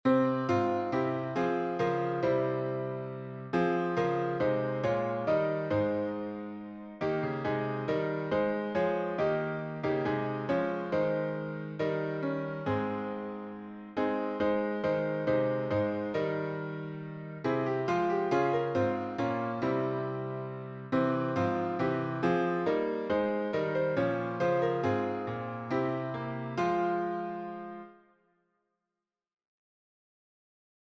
Synagogue melody